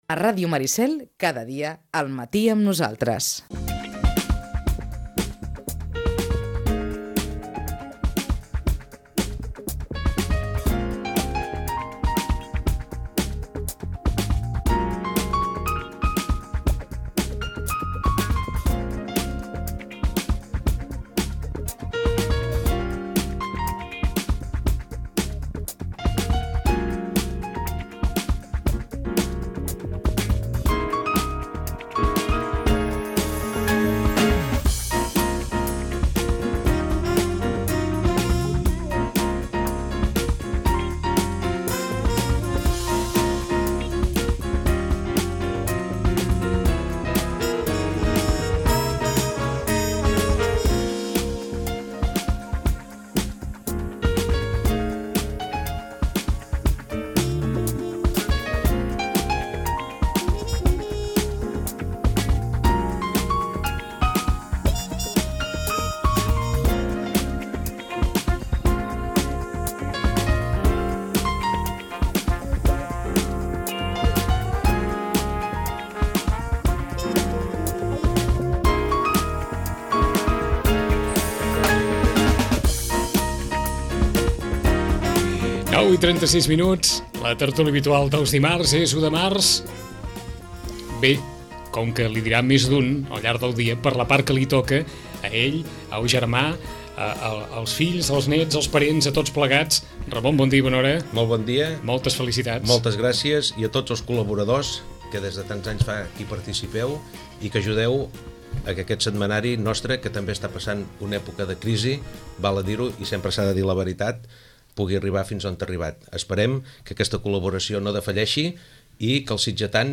Tertúlia